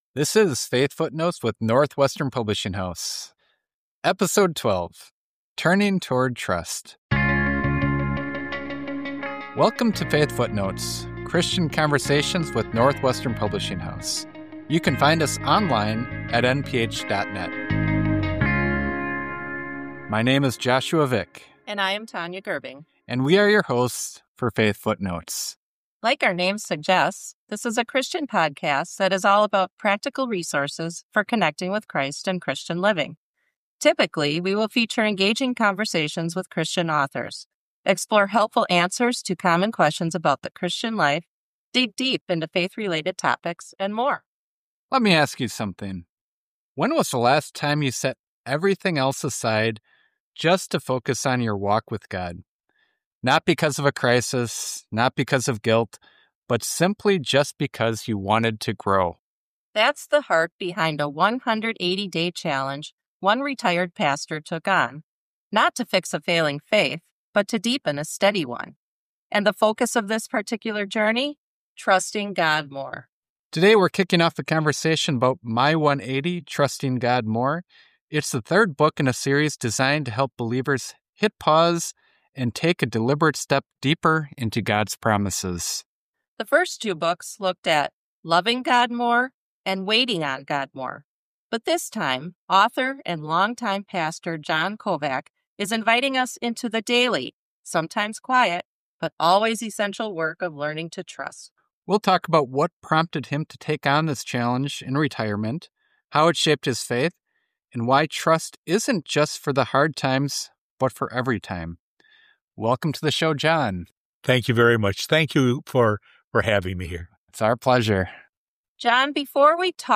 Christian Conversations with NPH